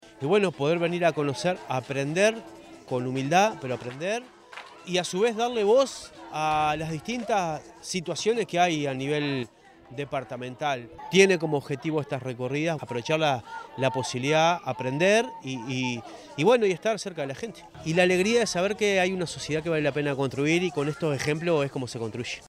marcelo_metediera_-_intendente_interino_de_canelones_1.mp3